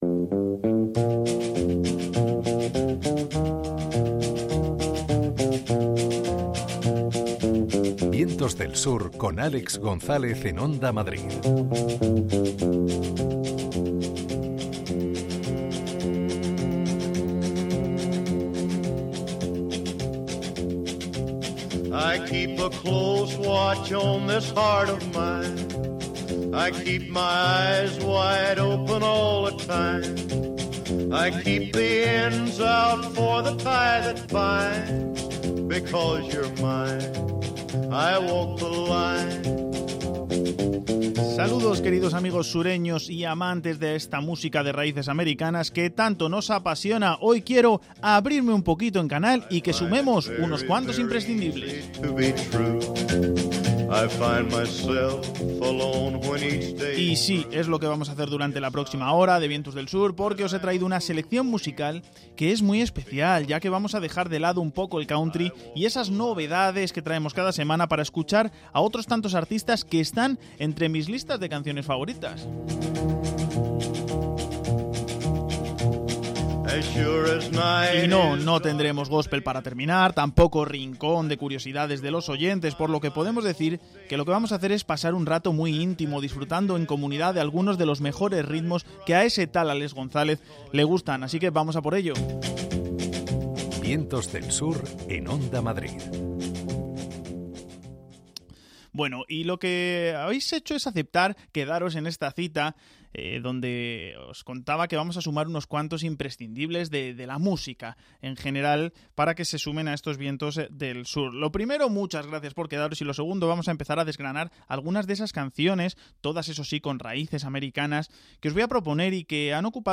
selección musical personal con raíces americanas